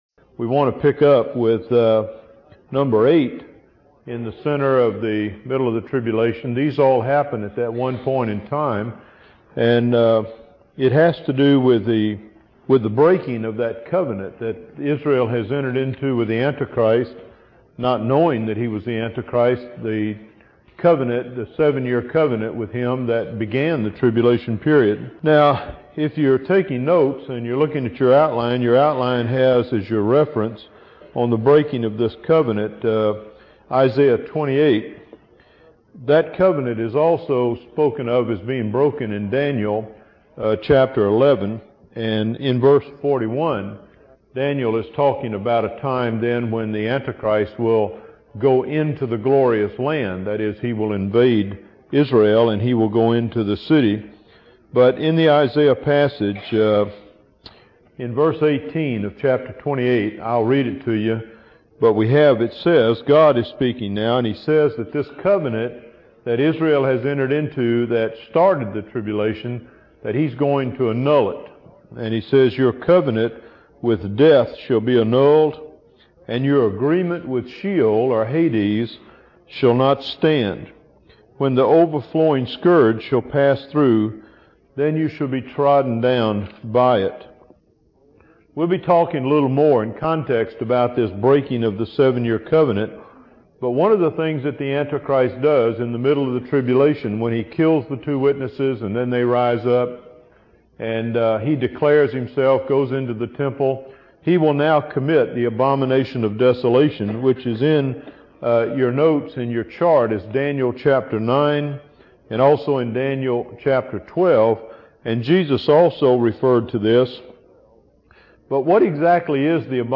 There are a few glitches with the recordings in some places.